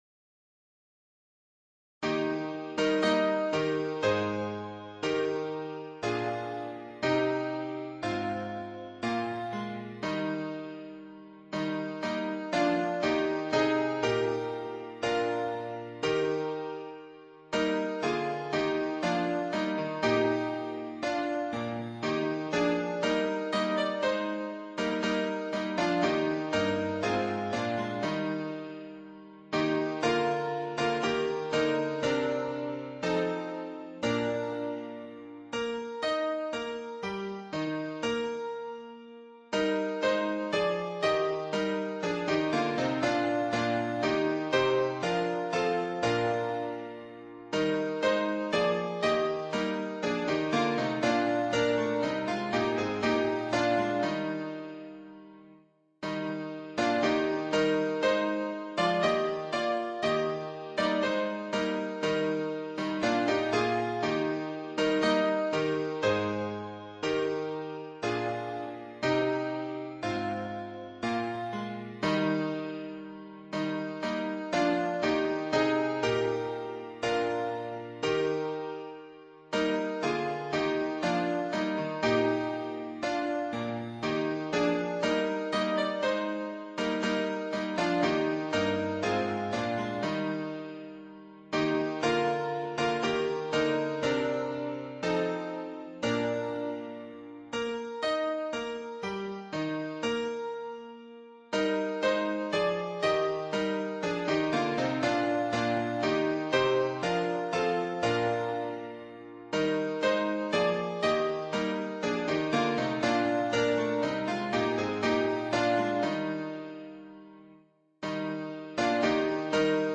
伴奏
原唱